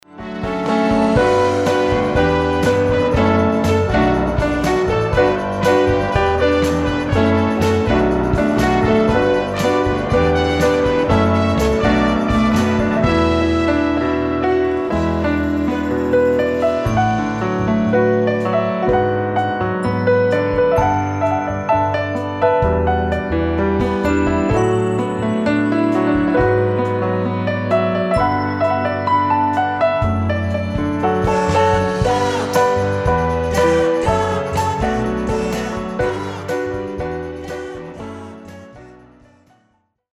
Pianist
instrumental recordings